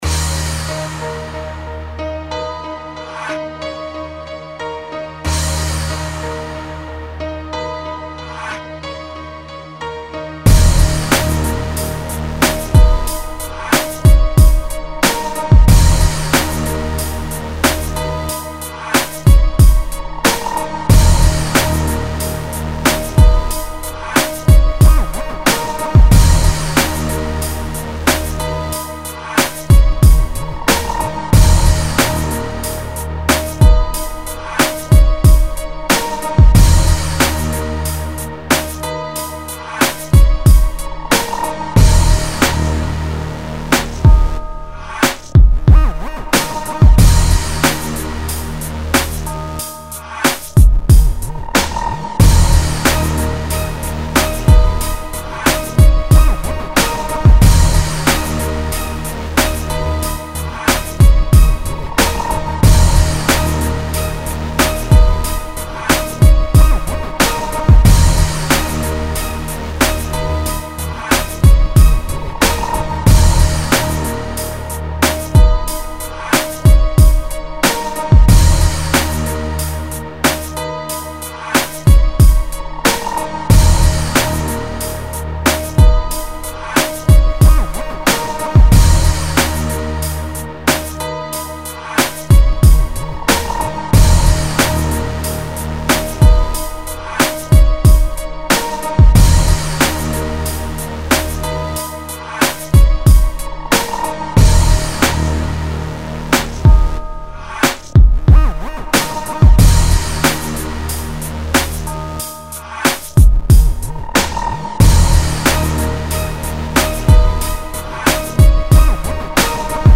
100 BPM.